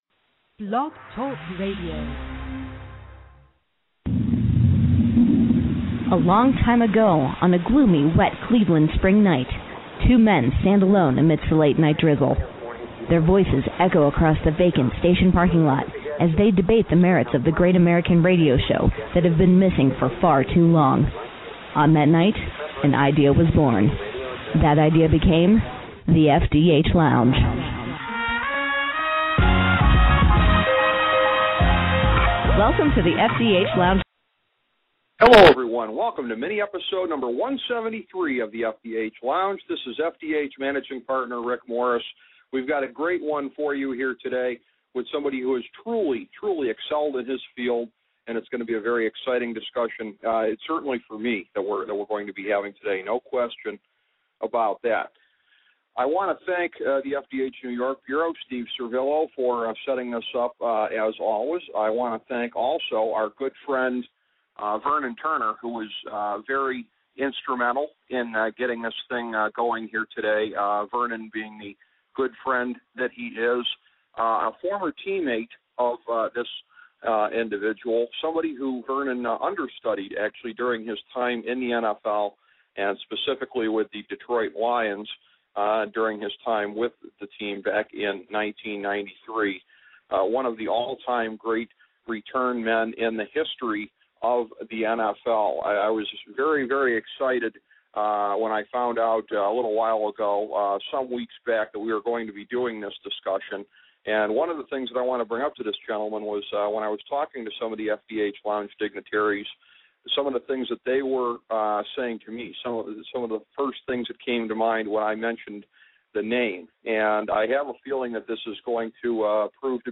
A conversation with NFL great Mel Gray